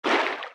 Sfx_creature_trivalve_swim_fast_07.ogg